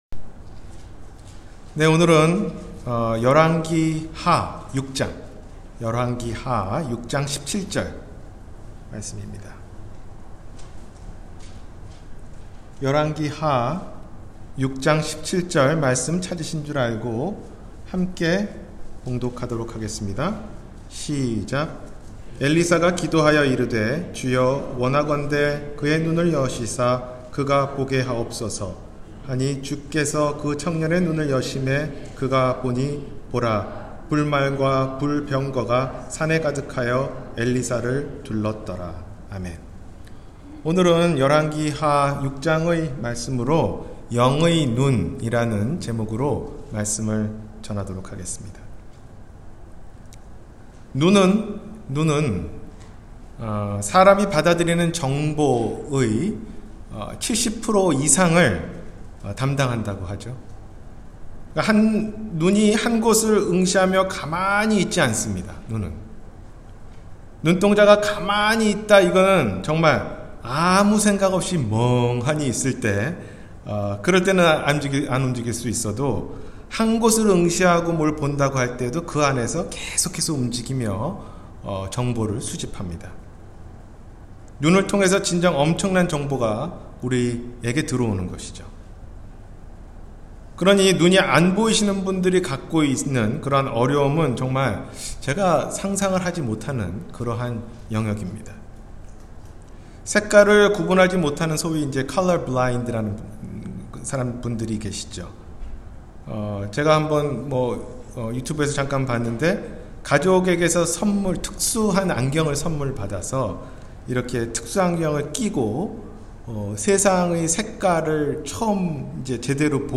영의 눈 – 주일설교